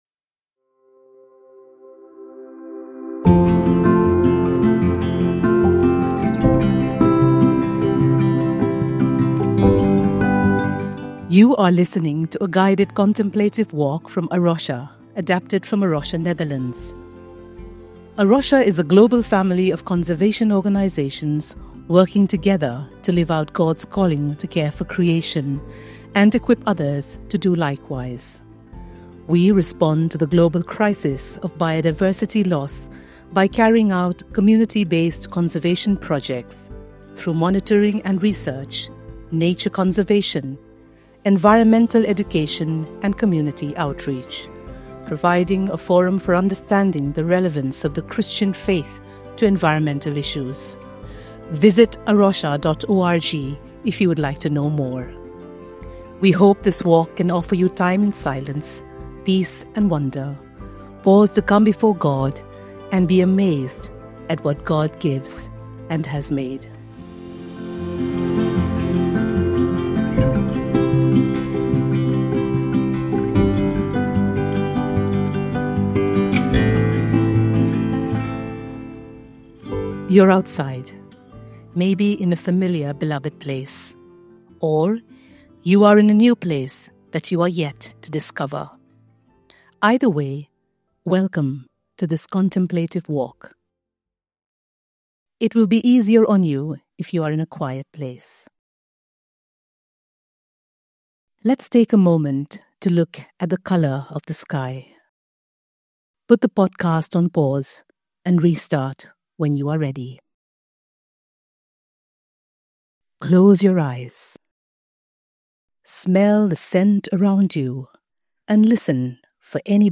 Guided nature prayer walk
Contemplative-prayer-walk.mp3